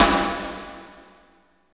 50.02 SNR.wav